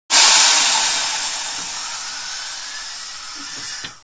gas_leak.wav